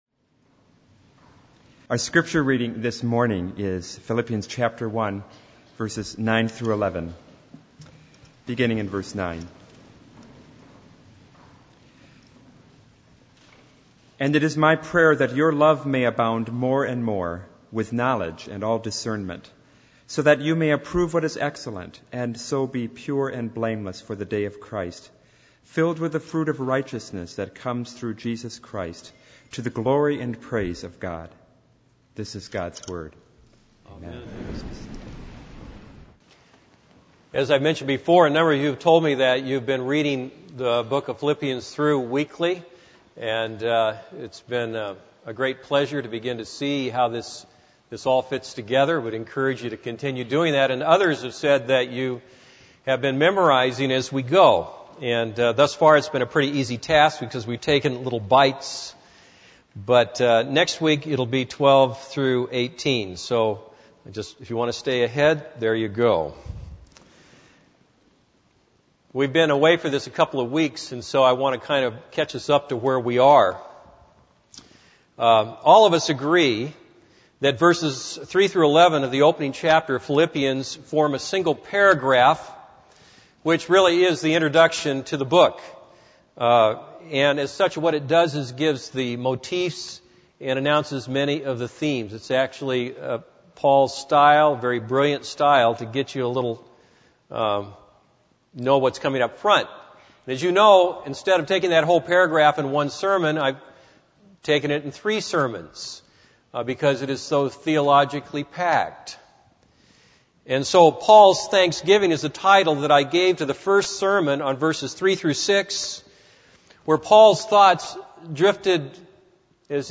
This is a sermon on Philippians 1:9-11.